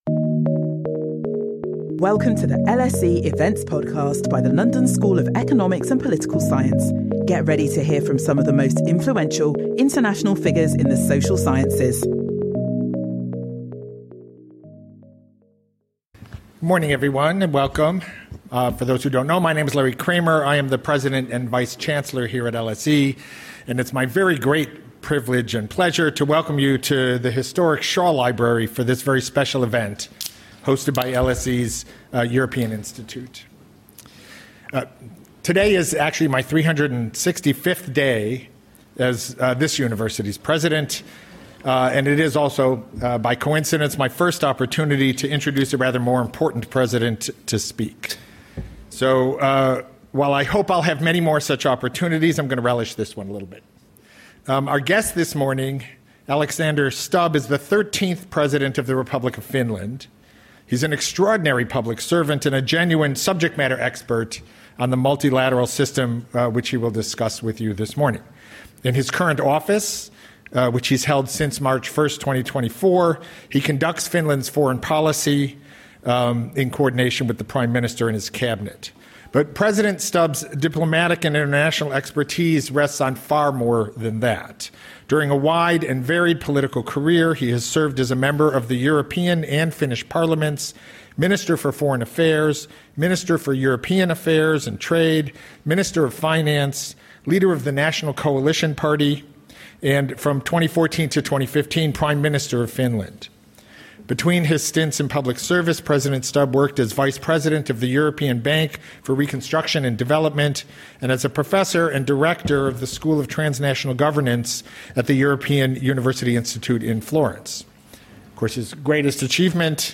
In conversation with Alexander Stubb
Join us for this special event with LSE alumnus and President of Finland Alexander Stubb.